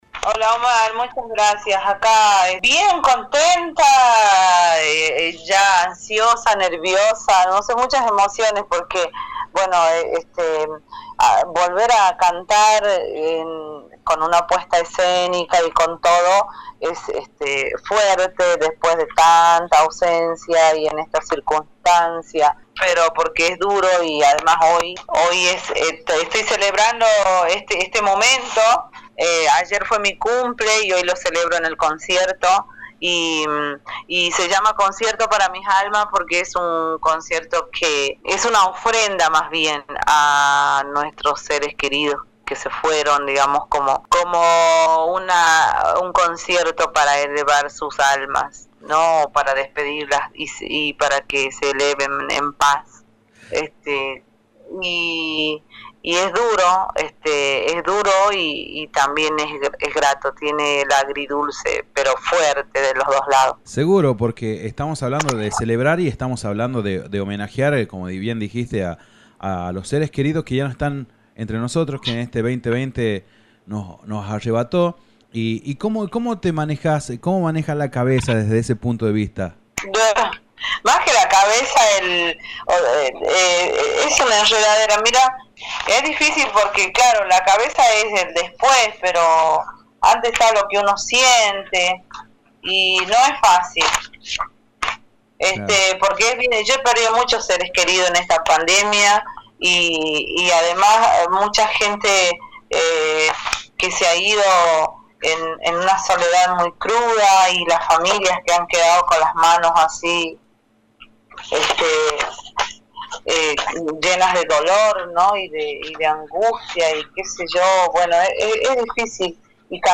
En comunicación telefónica con La Coplera Radio en el programa Suena Fuerte